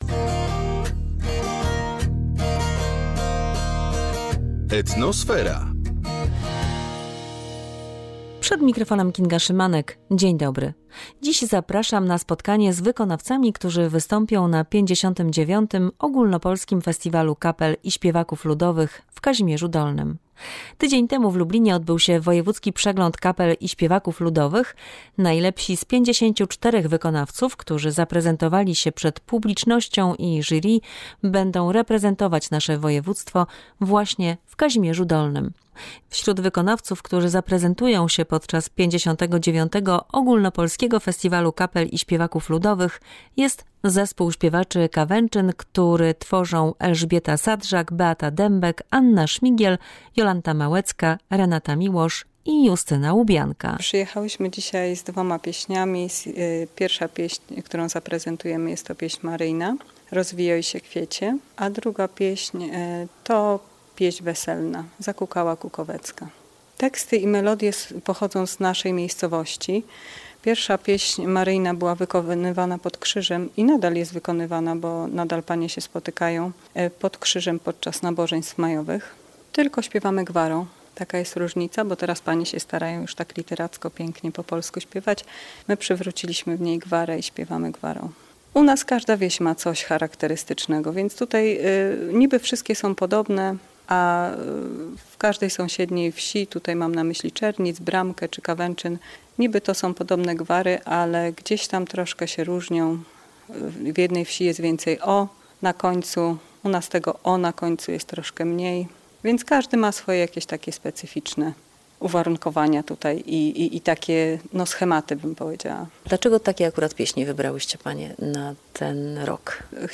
Dziś spotkamy się z zespołami śpiewaczymi i solistami, którzy będą reprezentować województwo lubelskie na 59. Ogólnopolskim Festiwalu Kapel i Śpiewaków Ludowych.